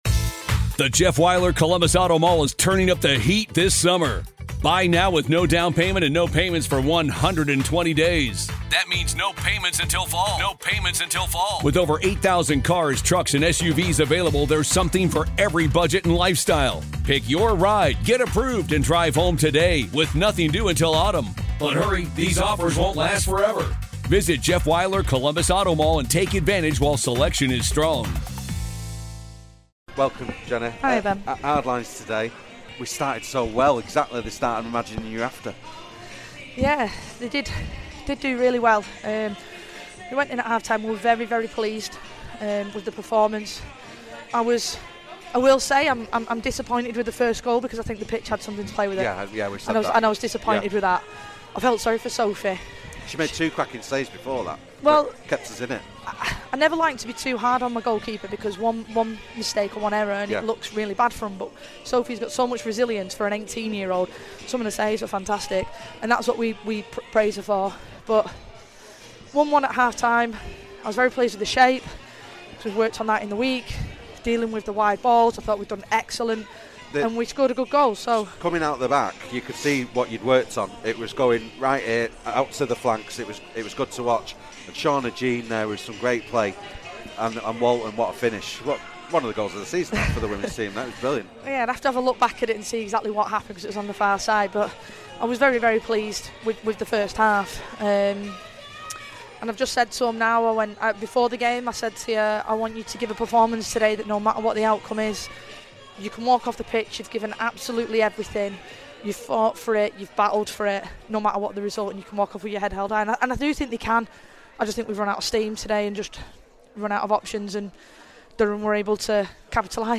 FCUM Radio / Post Match Interview